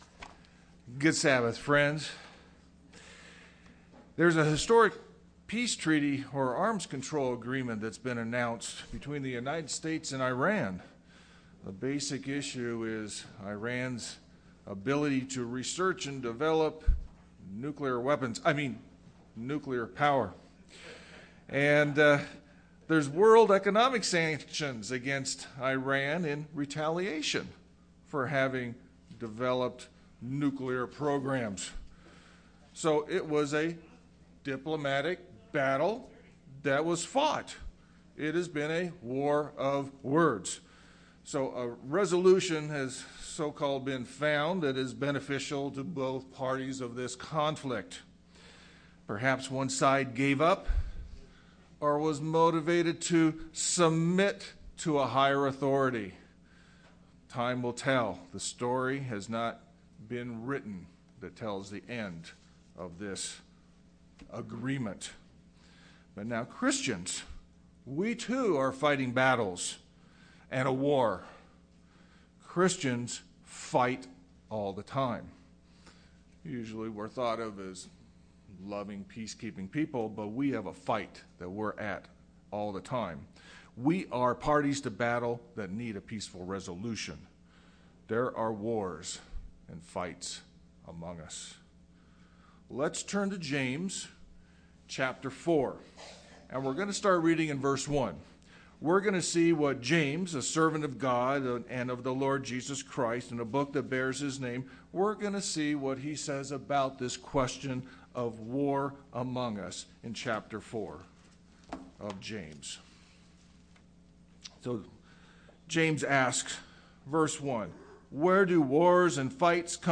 James 4:1-10 UCG Sermon Studying the bible?